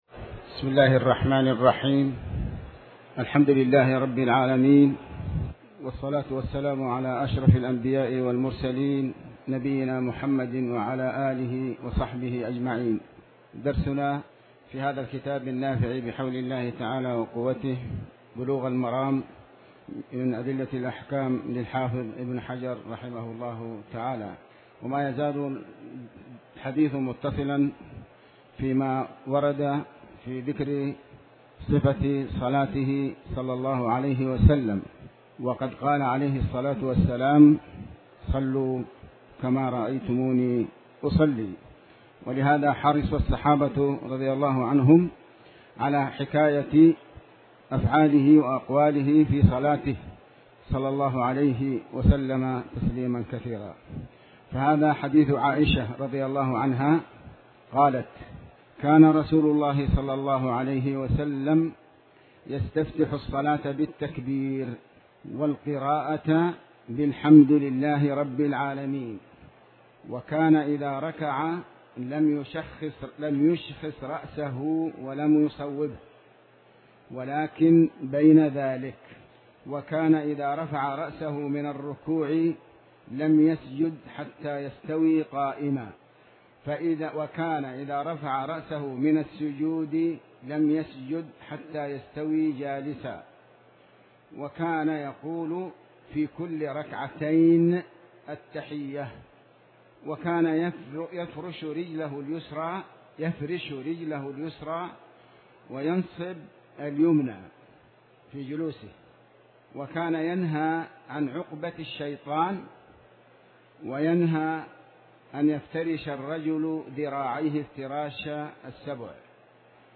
تاريخ النشر ٦ ذو القعدة ١٤٣٩ هـ المكان: المسجد الحرام الشيخ